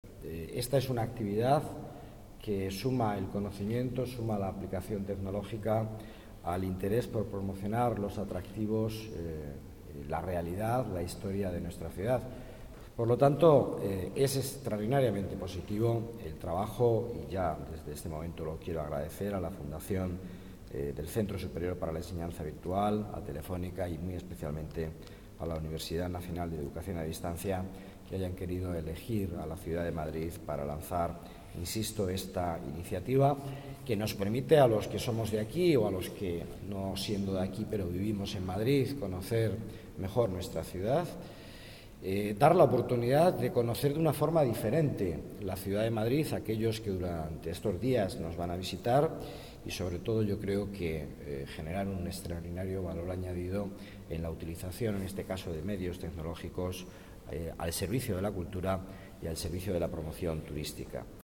Nueva ventana:Palabras del delegado Miguel Ángel Villanueva